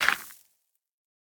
brushing_gravel_complete4.ogg